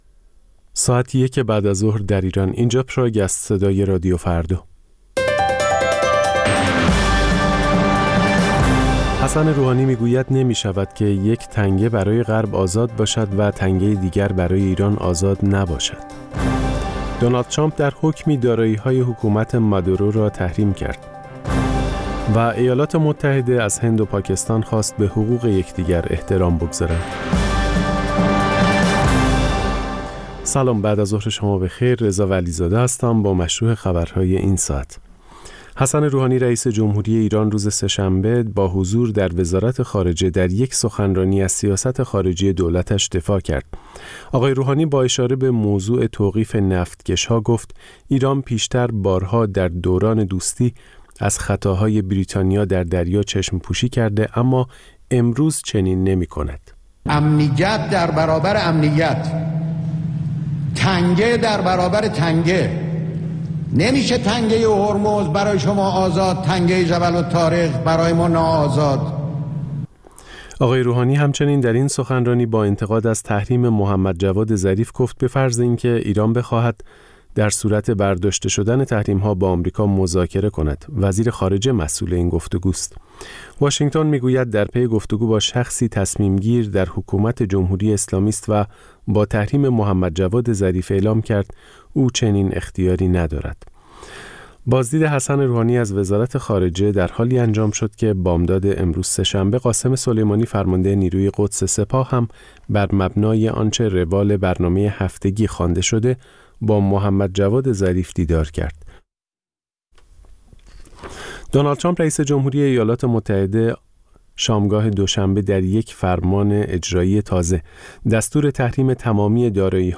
اخبار رادیو فردا، ساعت ۱۳:۰۰